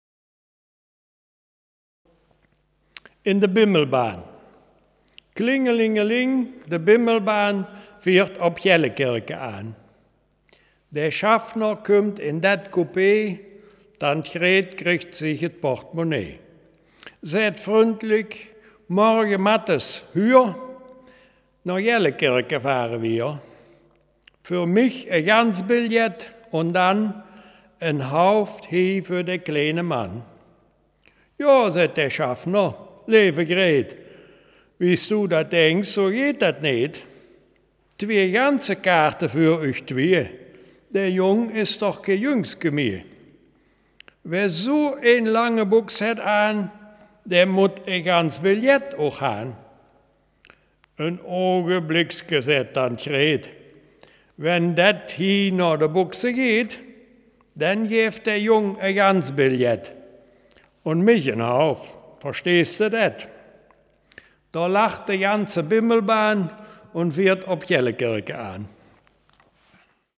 Gangelter-Waldfeuchter-Platt
Geschichte